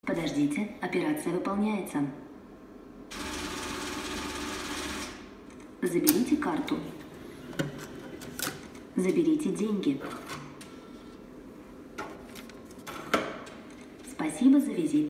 Звуковое сопровождение работы банкомата Сбербанка: голосовые сообщения при снятии наличных